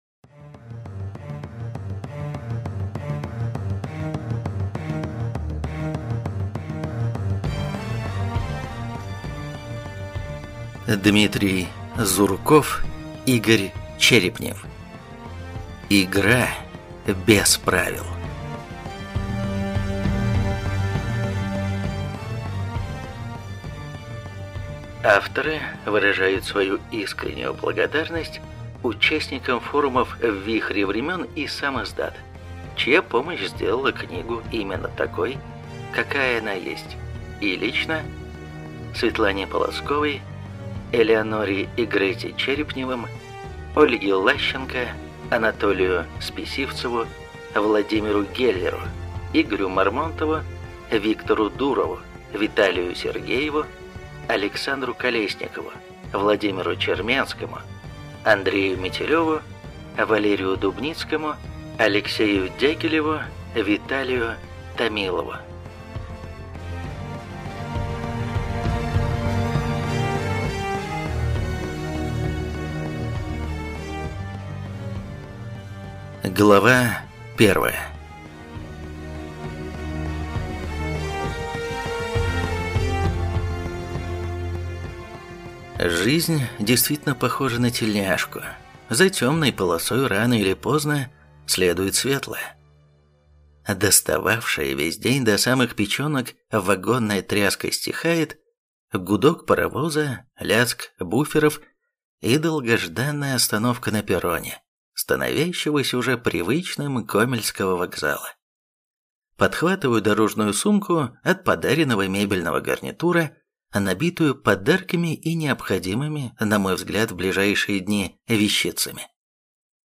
Аудиокнига Игра без правил | Библиотека аудиокниг
Прослушать и бесплатно скачать фрагмент аудиокниги